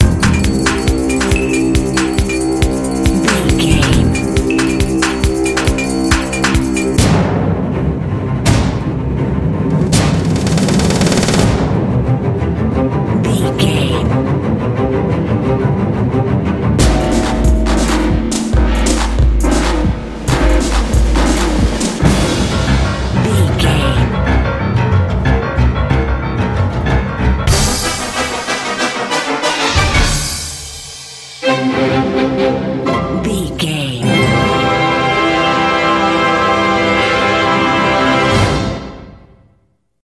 In-crescendo
Ionian/Major
dramatic
foreboding
percussion
strings
drums
brass
orchestra
film score